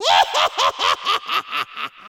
老巫婆可怕笑声音效_人物音效音效配乐_免费素材下载_提案神器
老巫婆可怕笑声音效免费音频素材下载